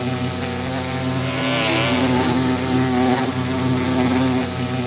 Minecraft Version Minecraft Version 25w18a Latest Release | Latest Snapshot 25w18a / assets / minecraft / sounds / mob / bee / aggressive2.ogg Compare With Compare With Latest Release | Latest Snapshot
aggressive2.ogg